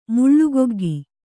♪ muḷḷugoggi